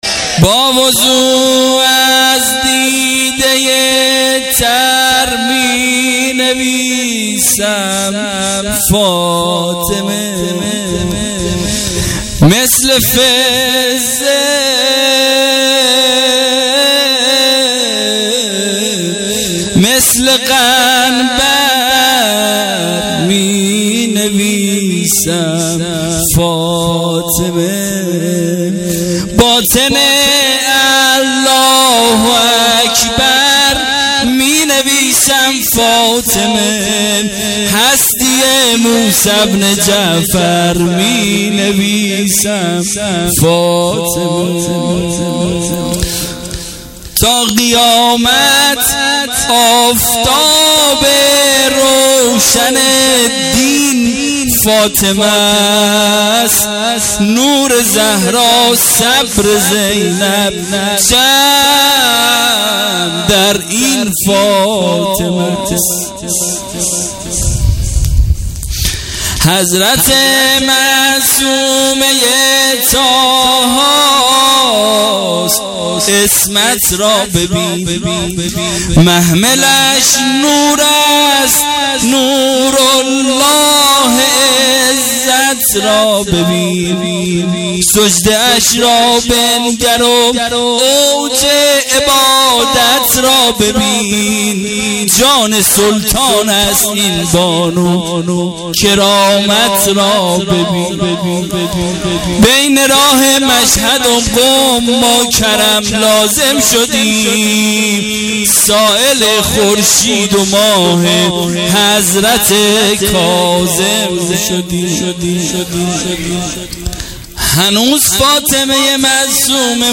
توسل به حضرت معصومه (س)98